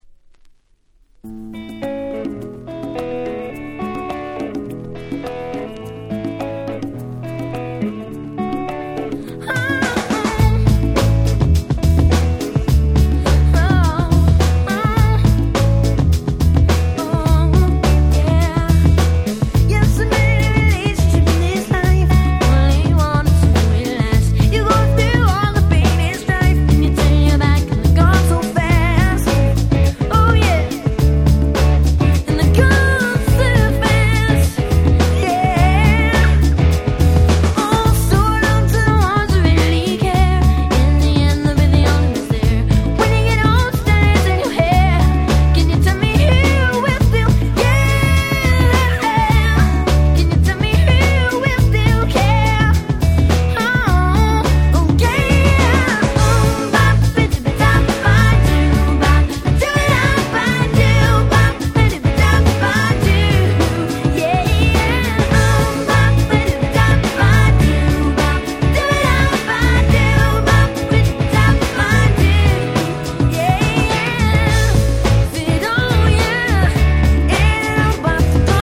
97' 世界的大ヒットPops !!